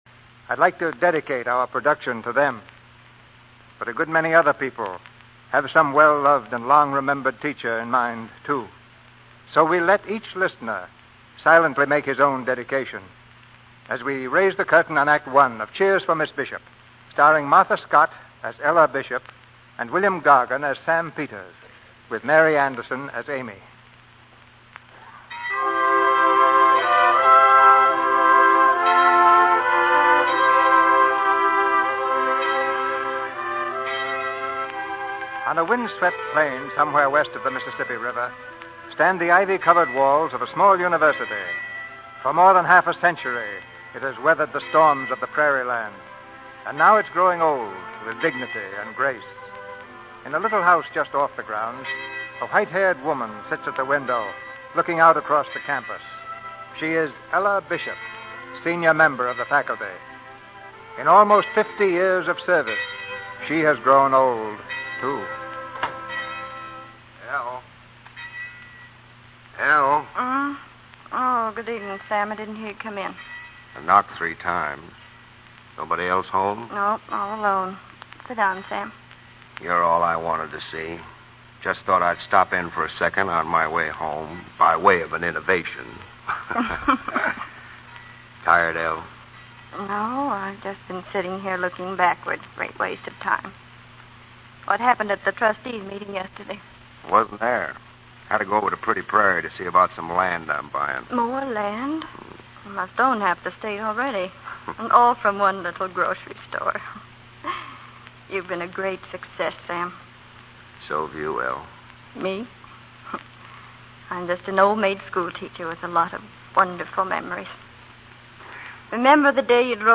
Lux_41-03-17_Opening_Missing_Cheers_For_Miss_Bishop.mp3
Cheers for Miss Bishop, starring Martha Scott, William Gargan, Griff Barnett